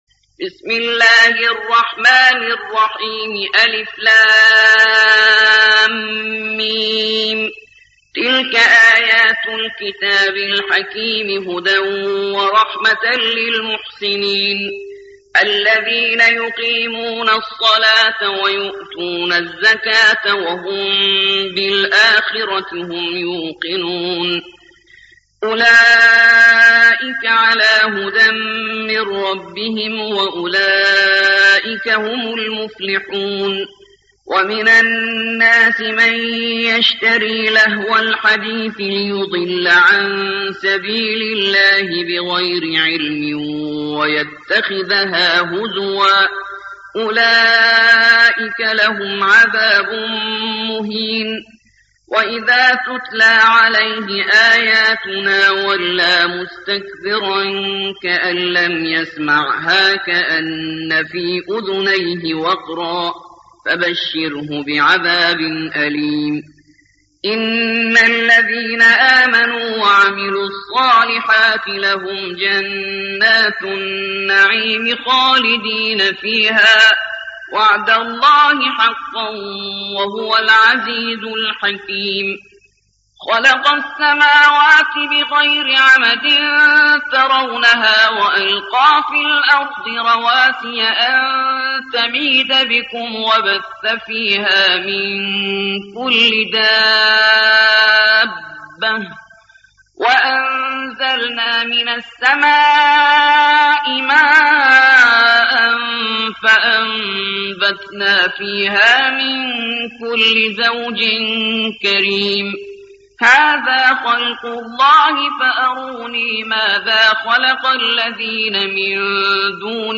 31. سورة لقمان / القارئ